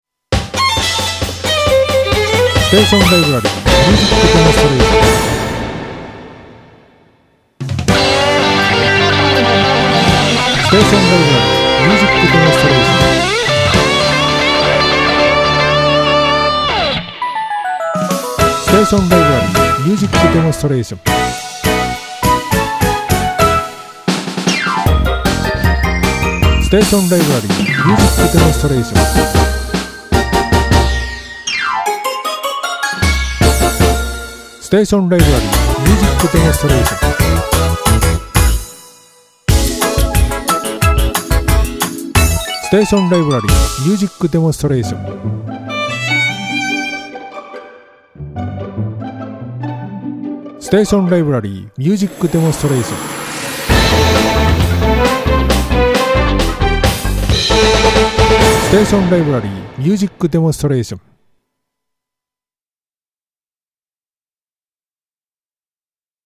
５秒から１1秒の短いジングル、アタック、ブリッジの特集です。ちょっとインパクトが欲しい時などにお役立て下さい。